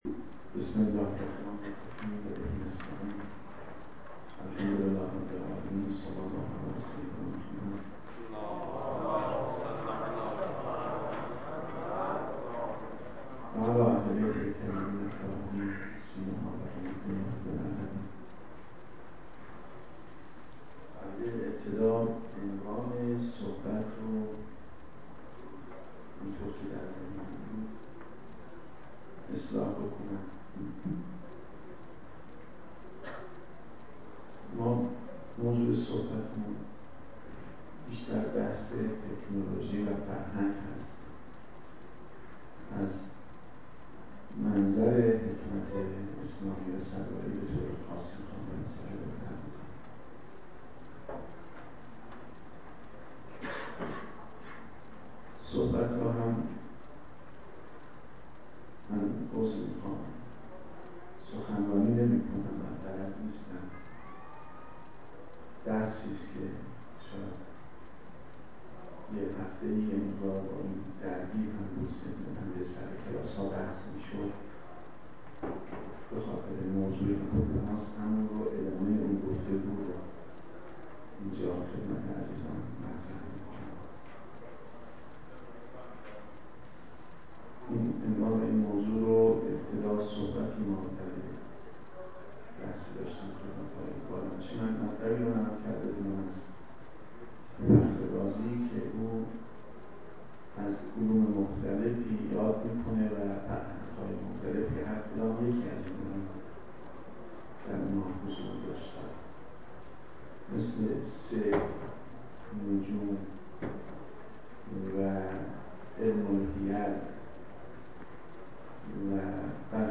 سخنرانی
در اولین همایش ملی فرهنگ و تکنولوژی